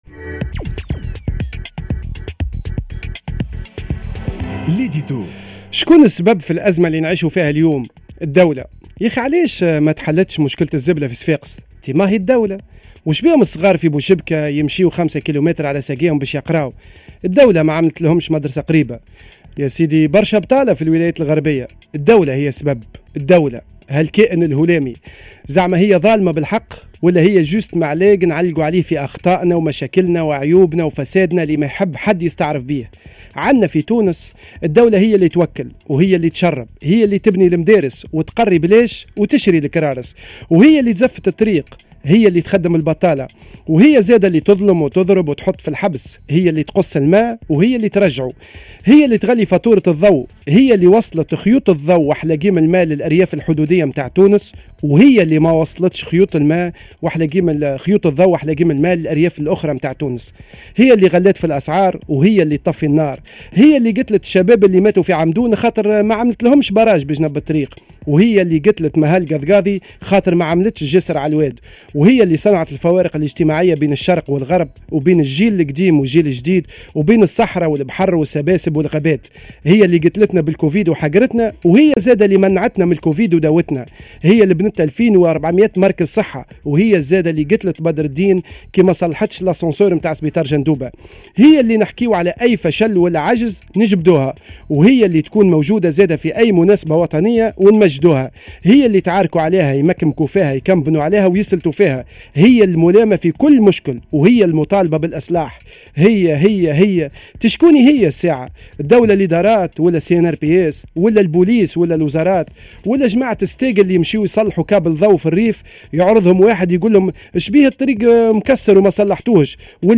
l'édito